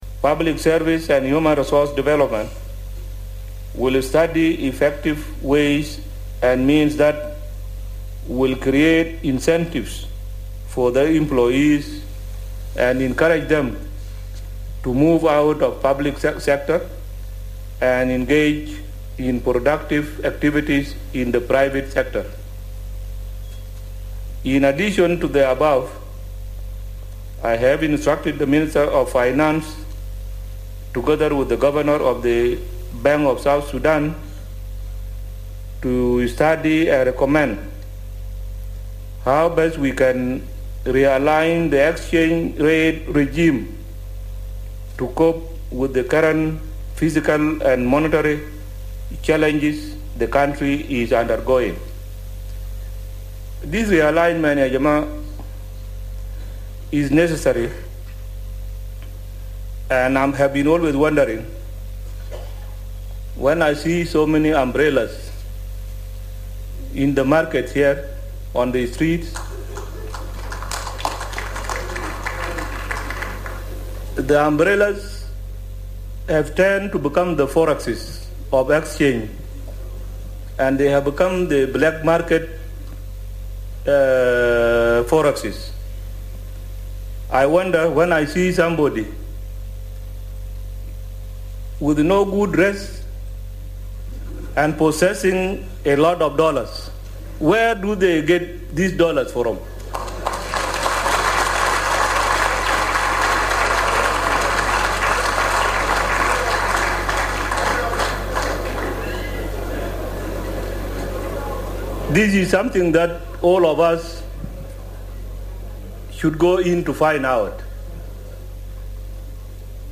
In a state of the nation address at the National Legislature on Wednesday, President Kiir said the government is taking steps to address the challenging economic situation in the country.
More in this six minute clip from his speech.